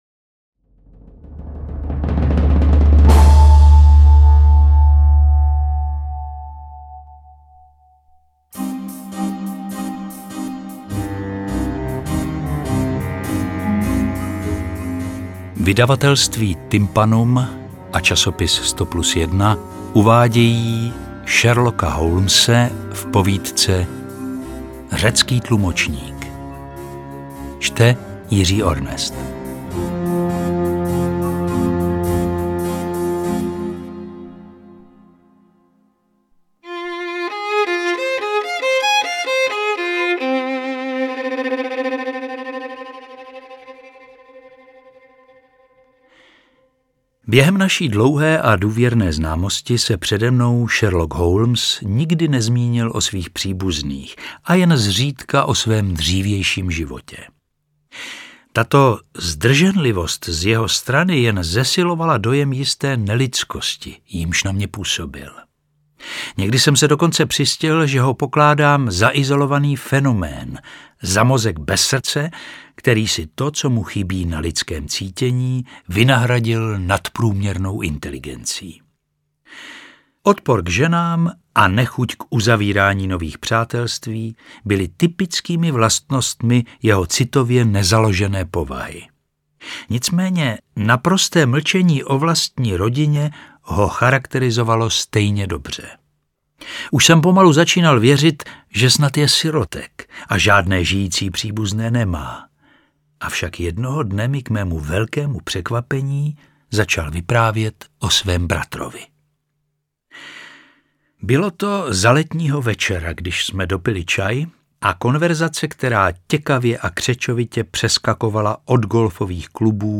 Interpret:  Jiří Ornest
Audiokniha - další příběh Sherlocka Holmese v podání Jiřího Ornesta.